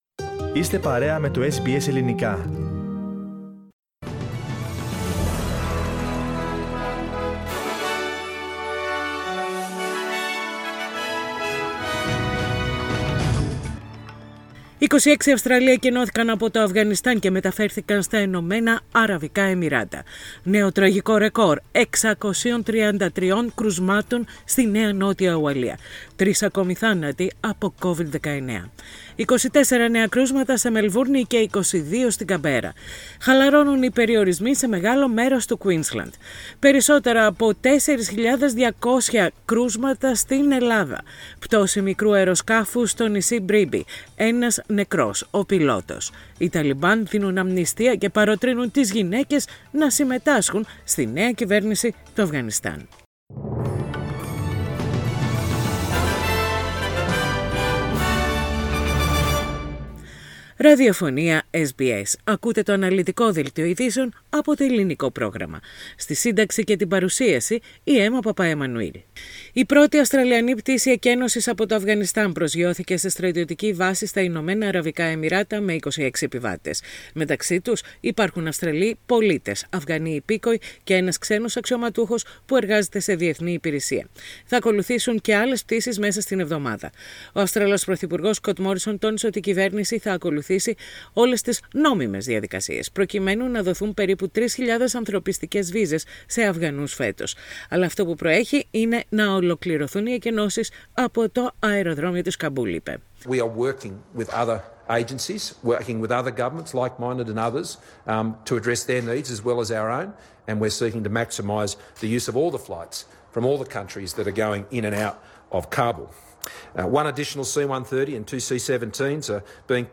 Πατήστε play στο podcast που συνοδεύει την αρχική φωτογραφία για να ακούσετε το δελτίο ειδήσεων στα Ελληνικά.